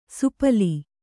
♪ supali